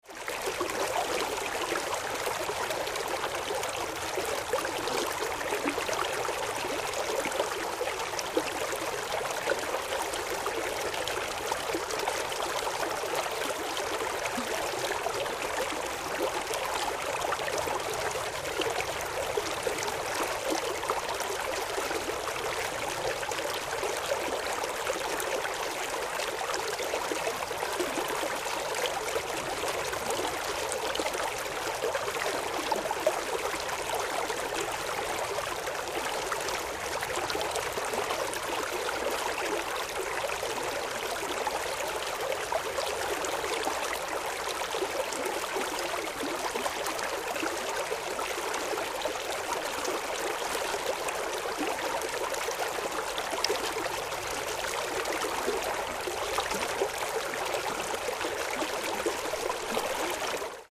simultaneous recording of a single stream at two different locations